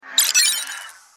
按钮.MP3